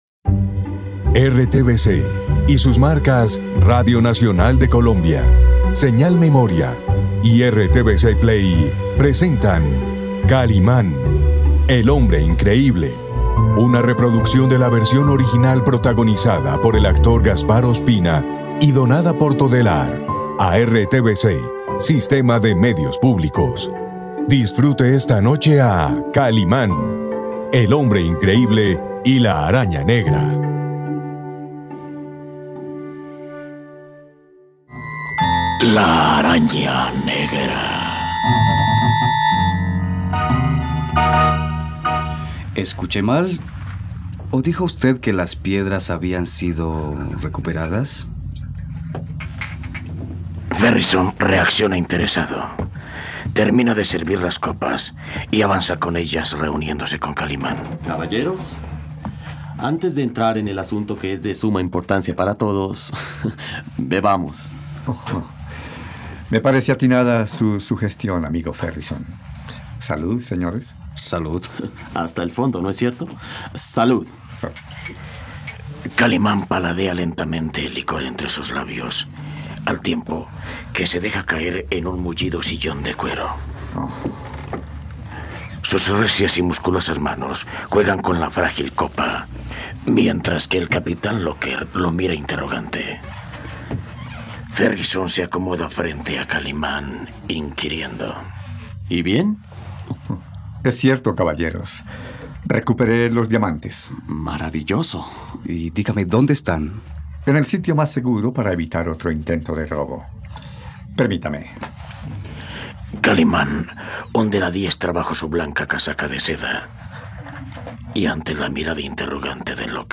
¡No te pierdas esta radionovela por RTVCPlay!